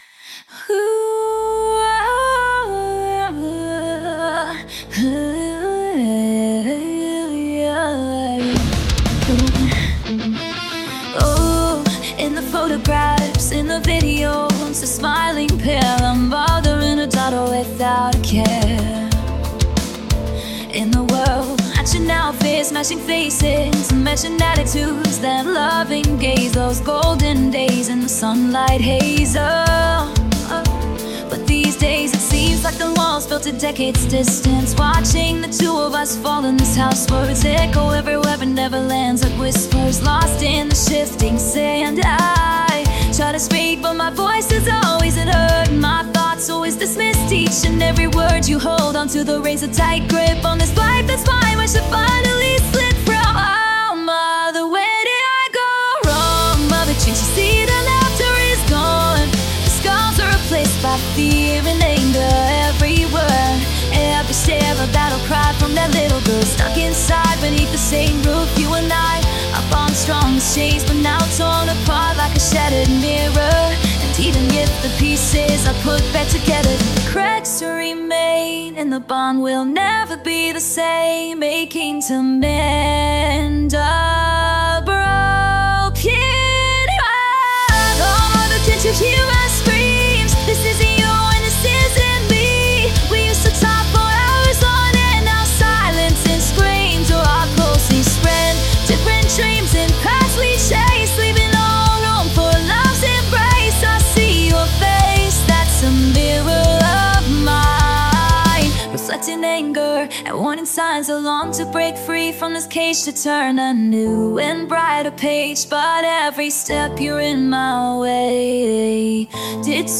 audio-to-audio singing-voice-conversion voice-conversion
Apply noise reduction to the converted vocals.
Apply autotune to the converted vocals.